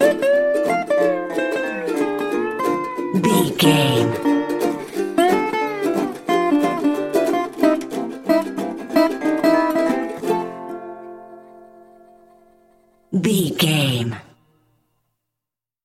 Ionian/Major
acoustic guitar
ukulele
slack key guitar